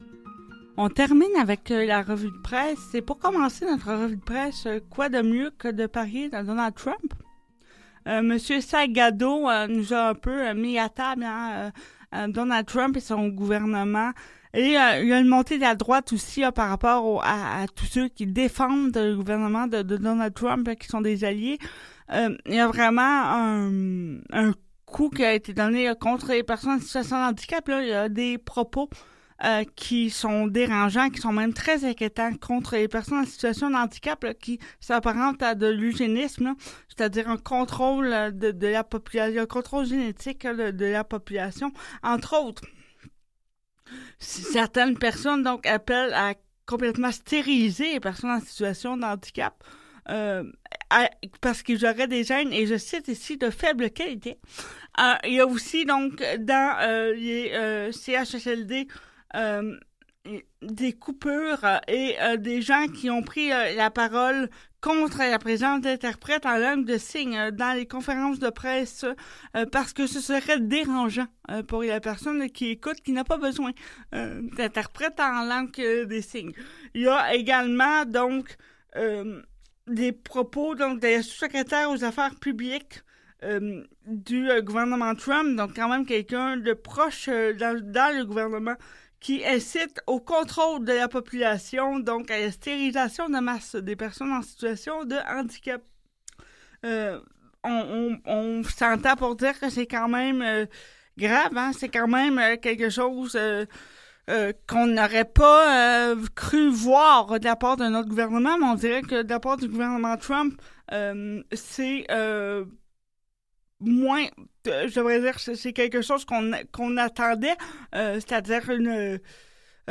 Revue de presse handicap et inclusion - Sans Détour, 7 avril 2025